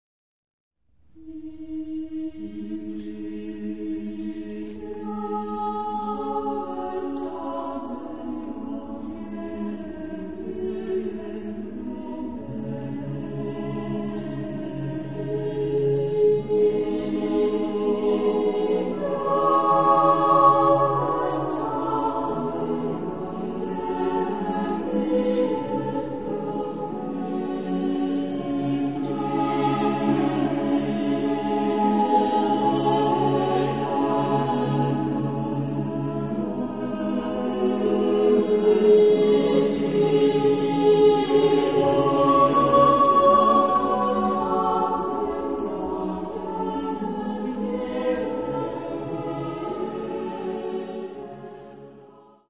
Genre-Stil-Form: Renaissance ; geistlich ; Motette
Chorgattung: ATTBB  (5 Männerchor Stimmen )
Tonart(en): a-moll